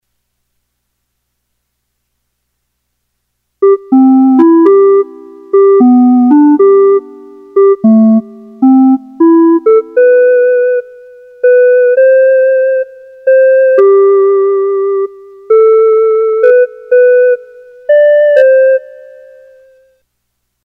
少し昔にテレビなどで聴いた、大阪のちょっと懐かしいメロディを、キーボードで再現してみました。